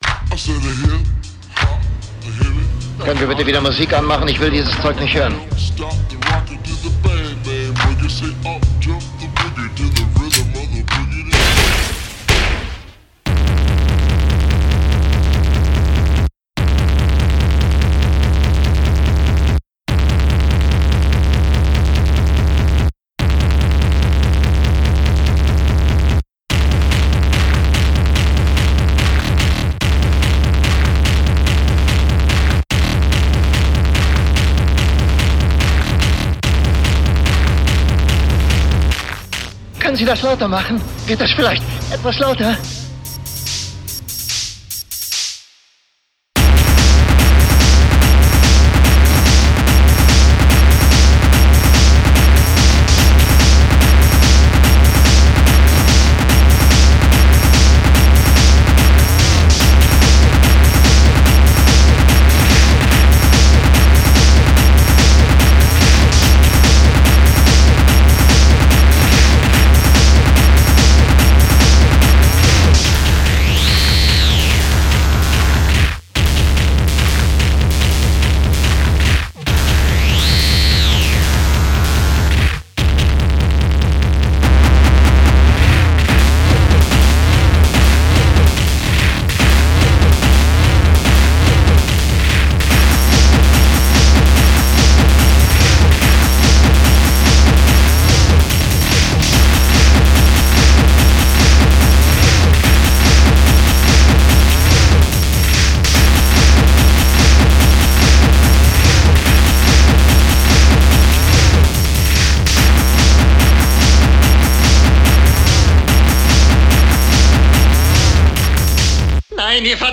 EBM, Industrial, Rhythmic Noise